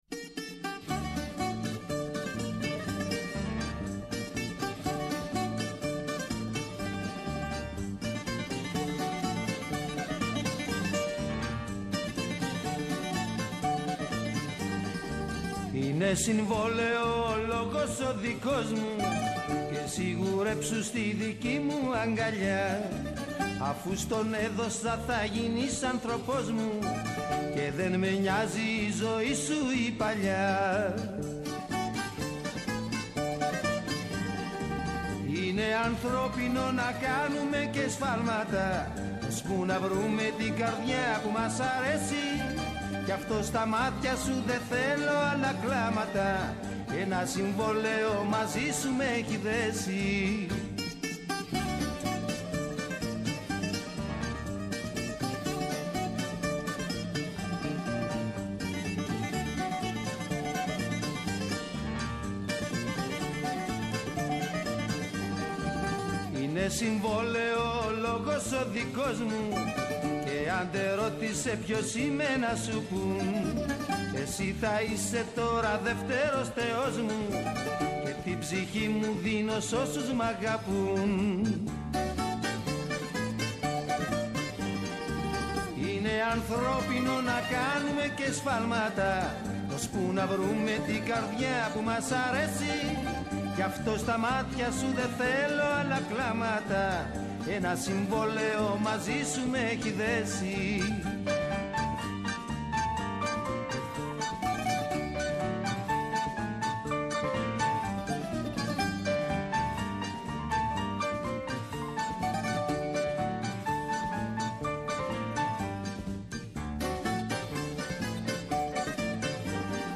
Καλεσμένοι τηλεφωνικά στην σημερινή εκπομπή:
-ο Χάρης Καστανίδης, βουλευτής ΠΑΣΟΚ-ΚΙΝΑΛ, υποψήφιος βουλευτής στην Α’ Θεσσαλονίκης